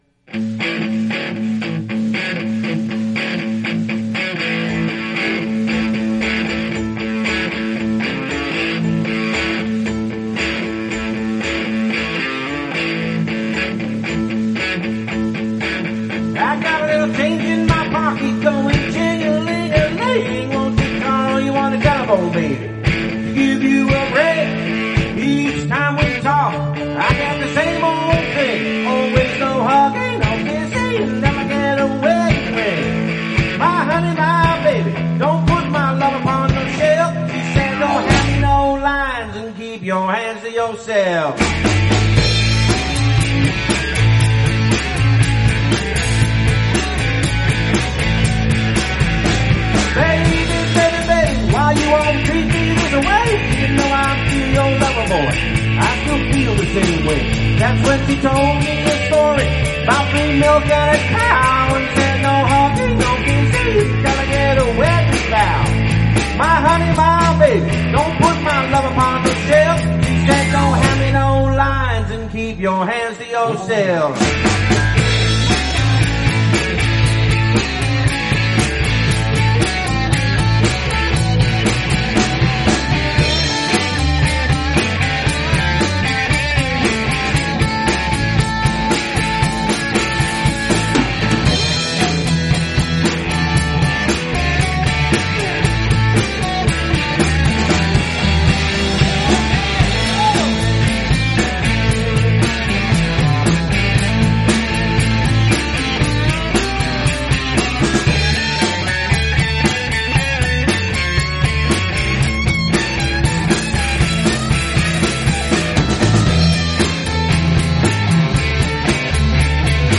Guitar, Keyboards, Vocals
Bass, Vocals
Guitar, Vocals, Keys, Harmonica
Drums, Vocals